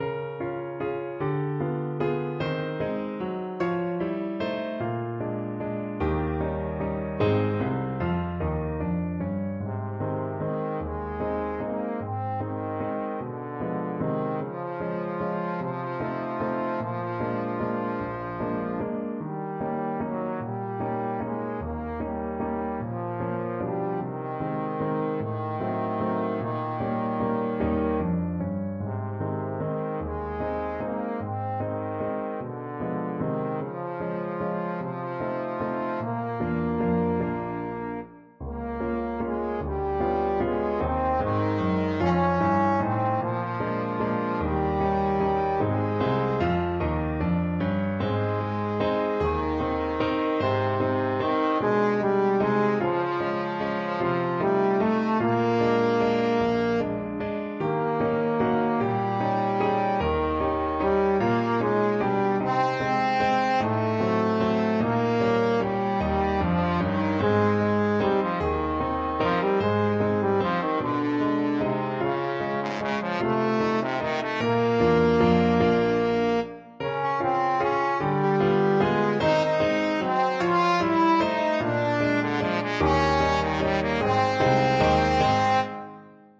Trombone
Moderato . = c. 50
3/4 (View more 3/4 Music)
Ab3-F5
Ab major (Sounding Pitch) (View more Ab major Music for Trombone )
Pop (View more Pop Trombone Music)